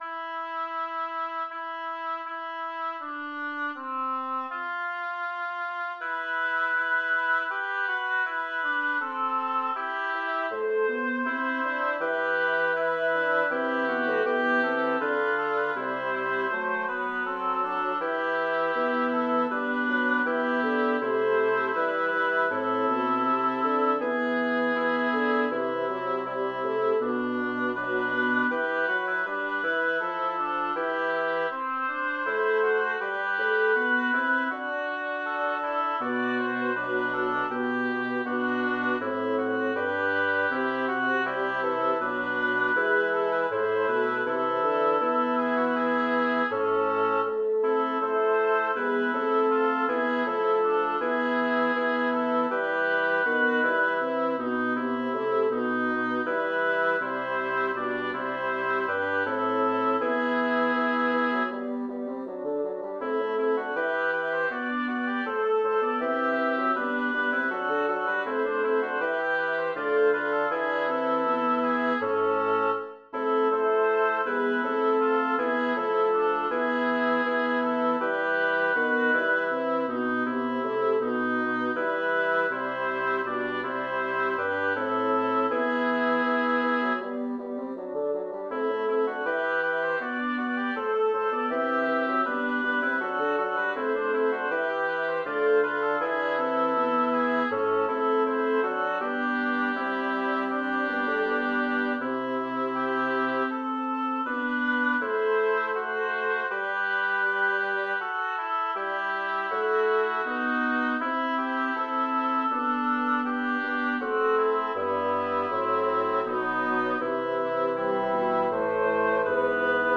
Title: Io son ferito Amore Composer: Hans Leo Hassler Lyricist: Number of voices: 4vv Voicing: SATB Genre: Secular, Canzonetta
Language: Italian Instruments: A cappella